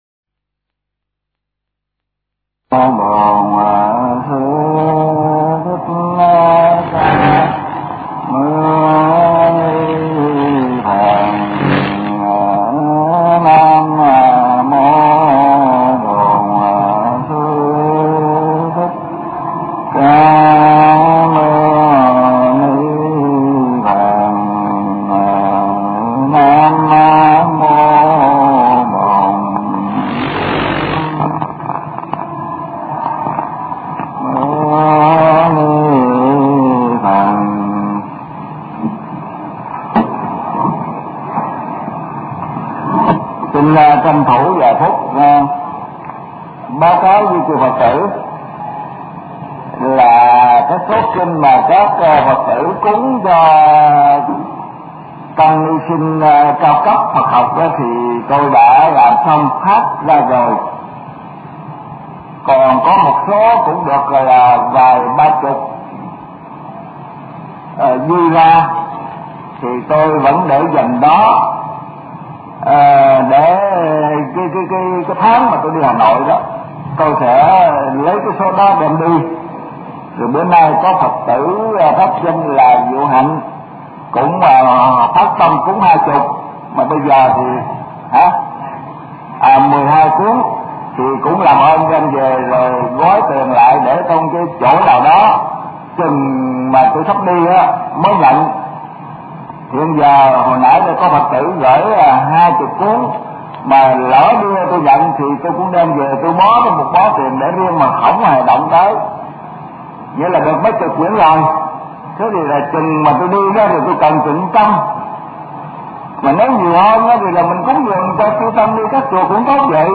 Kinh Giảng Kinh Pháp Hoa